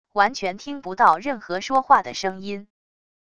完全听不到任何说话的声音wav音频